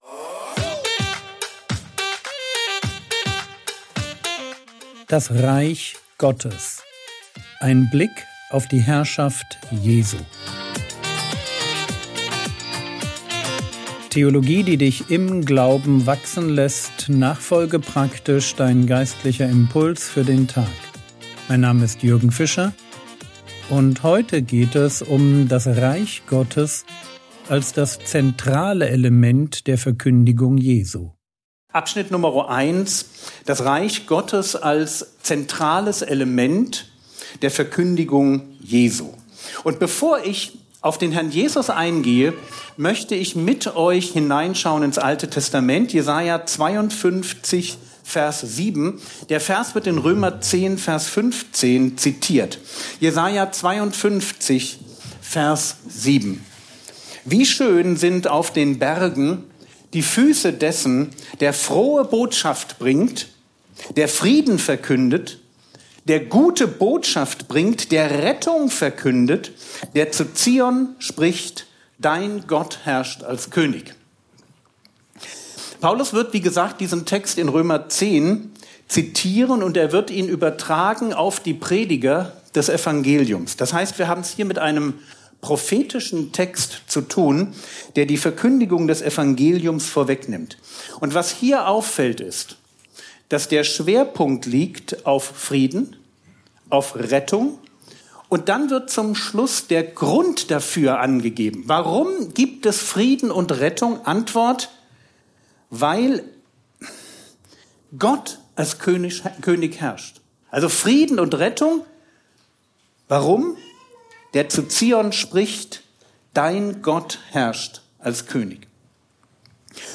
Vortrag Paderborn